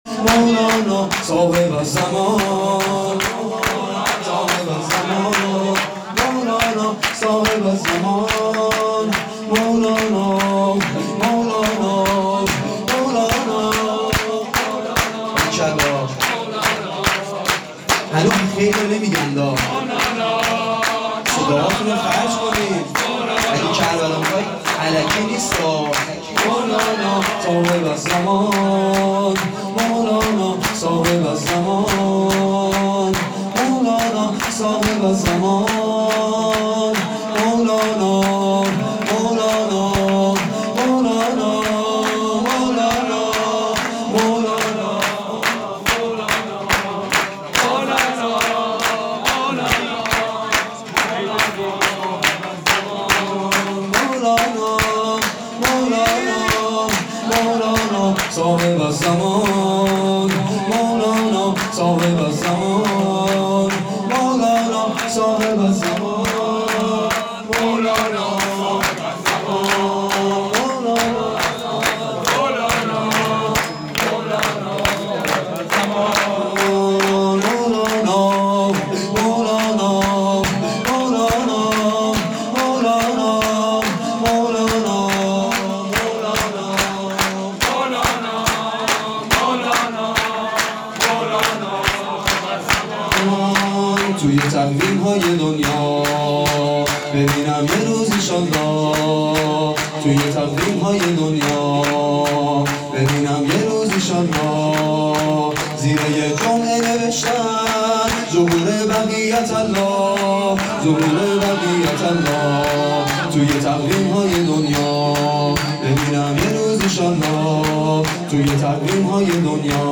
میلاد حضرت مهدی(عج)۹۸